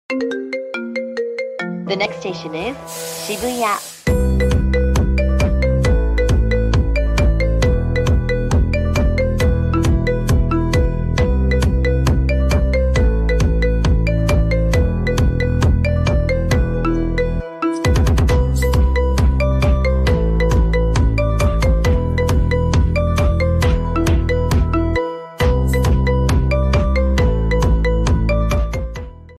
Marimba Ringtone